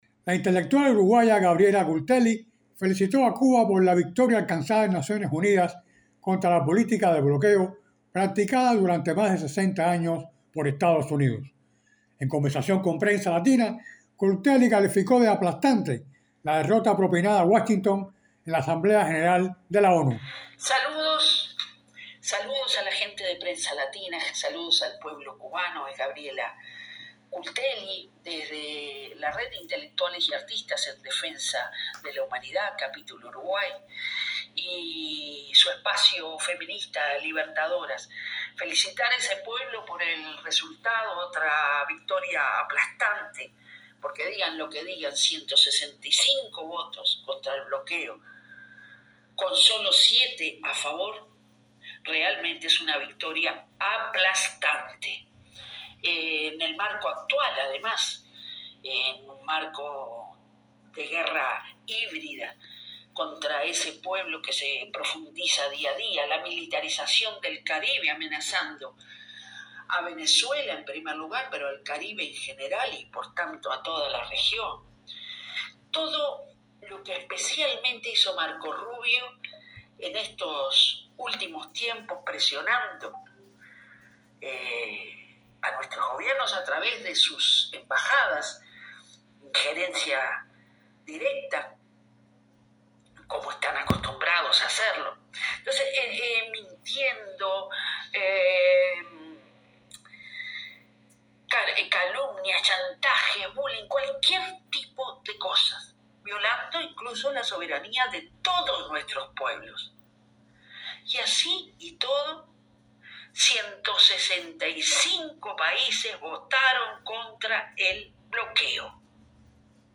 En conversación con Prensa Latina